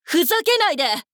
大人女性│女魔導師│リアクションボイス│商用利用可 フリーボイス素材 - freevoice4creators
怒る